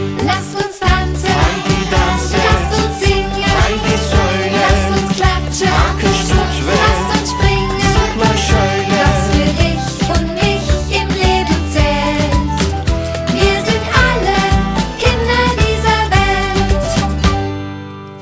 deutsch-türkisches Lied
deutsch-türkische Kinderlied